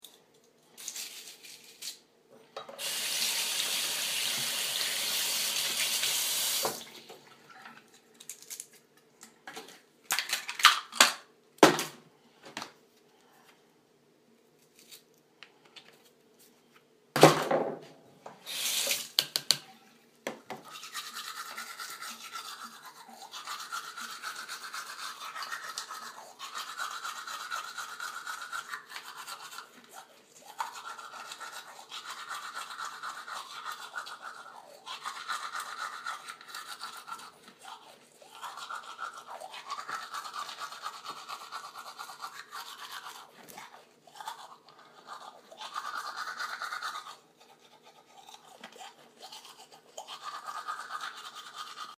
Field Recording 3/4/16
Sounds: Brushing Retainers, Closing Retainer Case, Sink Running, Tapping Tooth Brush, Teeth Brushing.
Field-Recording-Teeth-Brushing.mp3